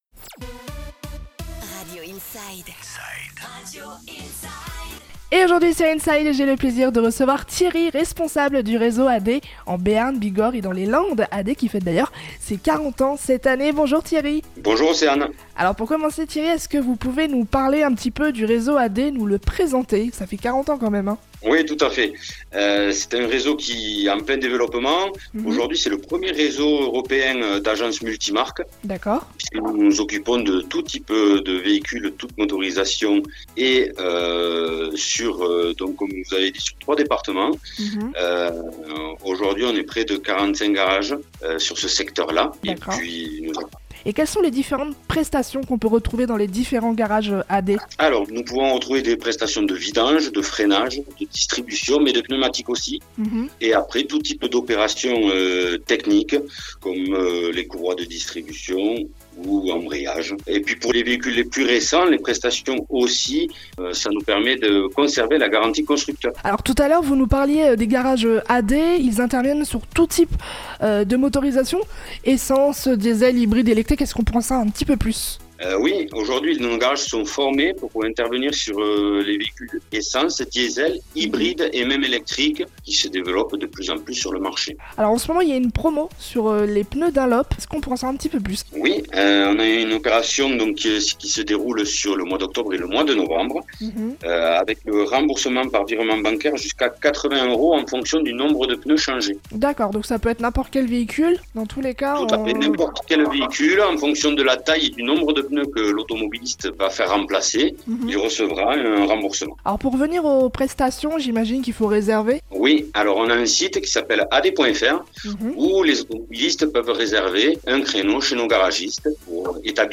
Interview du "Réseau AD" sur Radio Inside